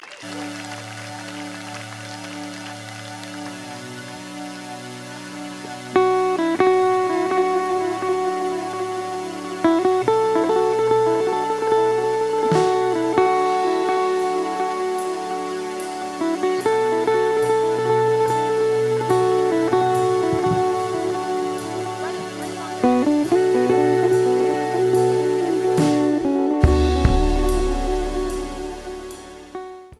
New Orleans, LA - May 2, 2014 Samsung Galaxy Stage